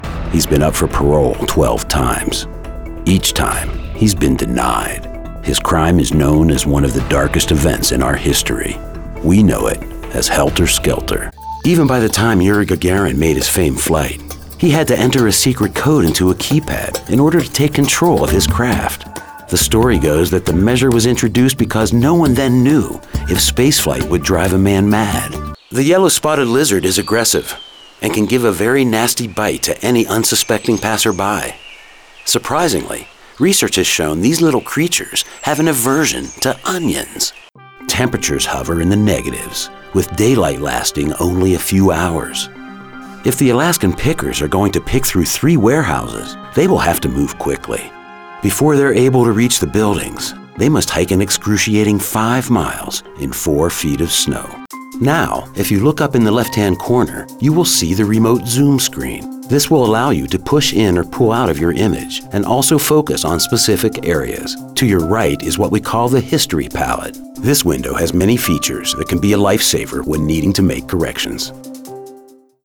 Penetrating, Urgent, Unique.
Narration